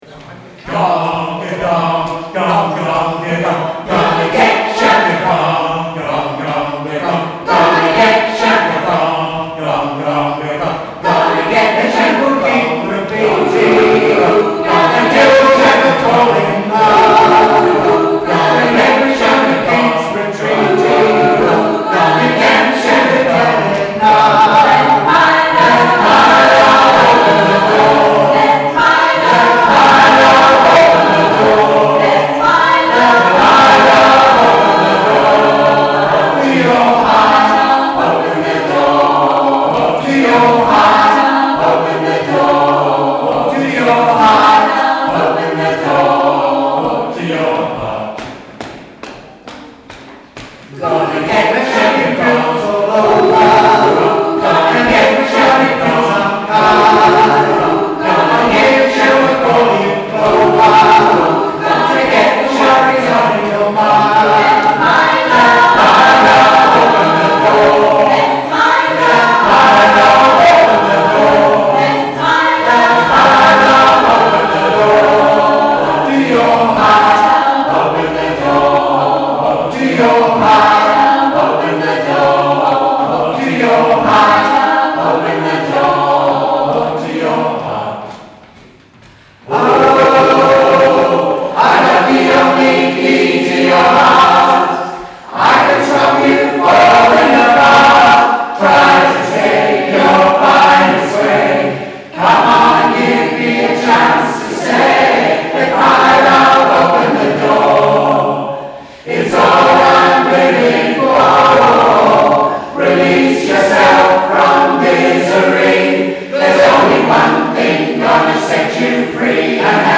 The recordings are a very rough and I’d forgotten to turn off autogain (doing two things at once: very silly) while recording, so I’ll hope you forgive the brassy sound.